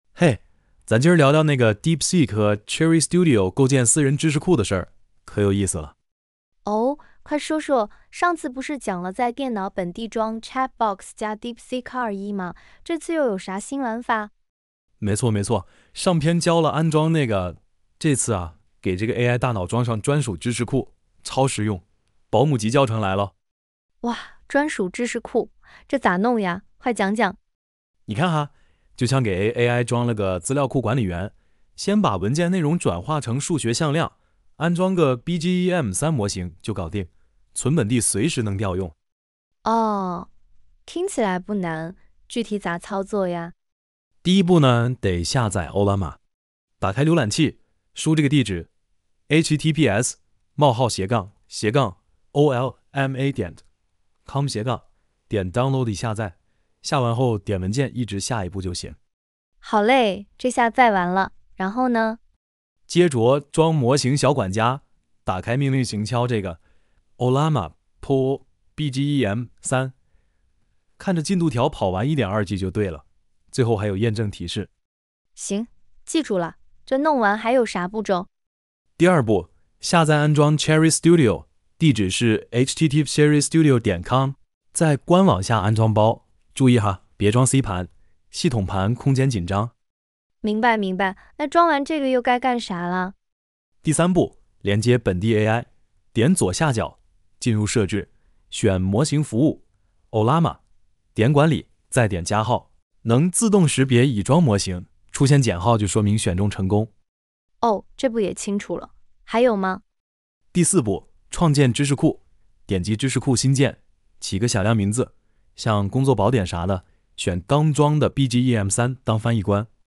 以下均由coze工作流生成：
4、提取男女声
5、提取男声和女声，这是2个分支